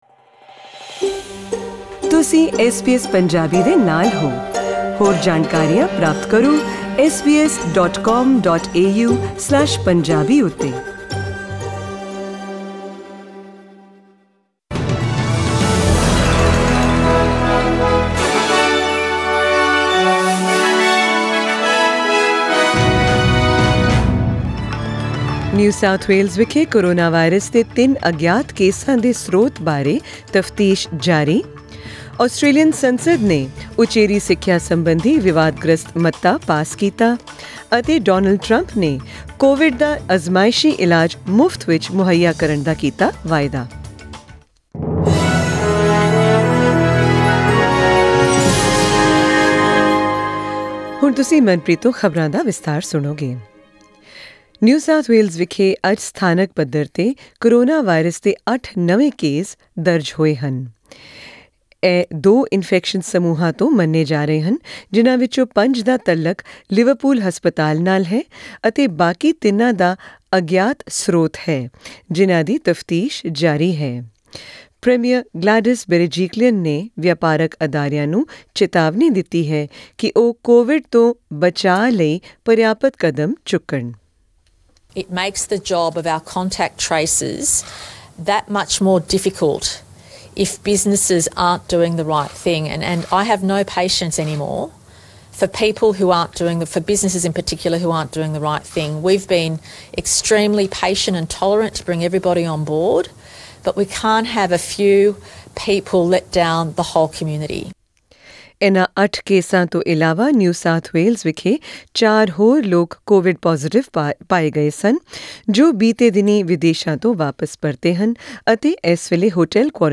In tonight’s SBS Punjabi news bulletin, hear the latest from around the nation about COVID-19 cases, Acting Immigration Minister Alan Tudge clarifies the new English language requirements for partner visa applicants, the Senate passes the contentious higher education bill while Donald Trump vows to make an experimental and unproven anti-viral drug free for all Americans.